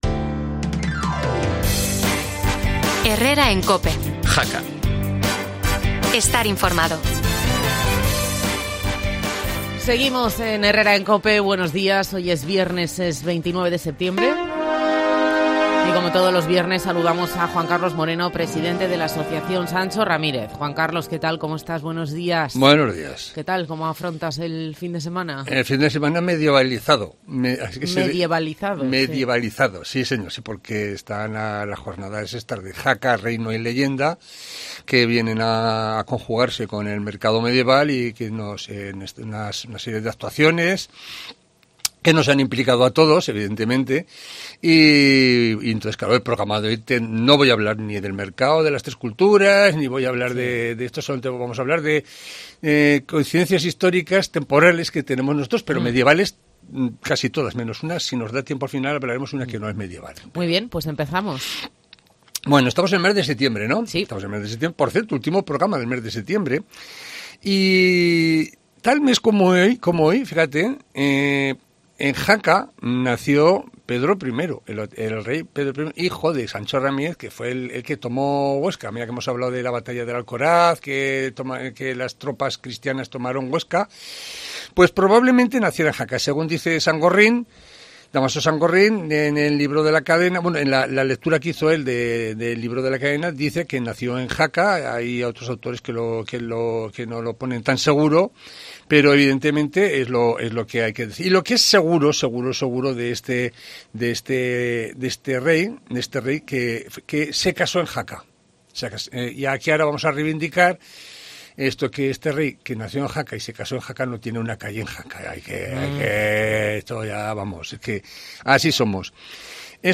También en septiembre pero del año 1.134 el rey Ramiro II confirma los fueros de Jaca. ¿Quieres saber más? No te pierdas la entrevista en COPE